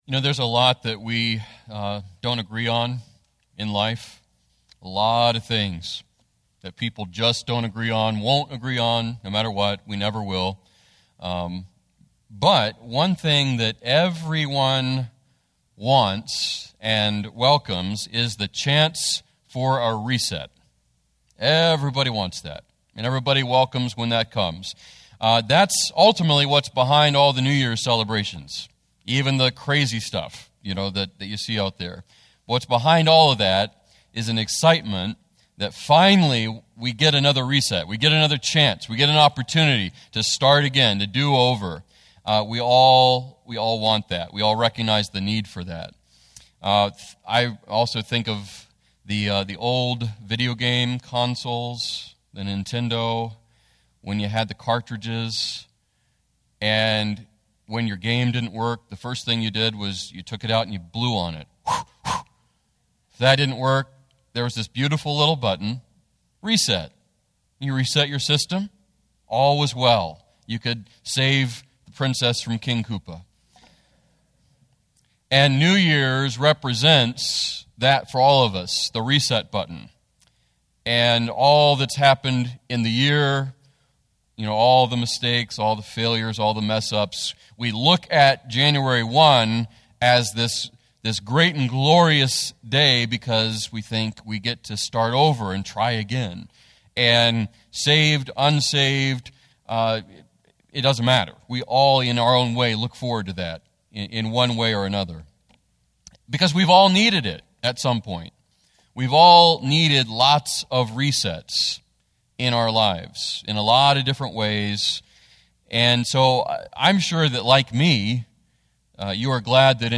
FBC Sermon Audio
Weekly messages from the pulpit of Faith Baptist Church, Prosperity, WV.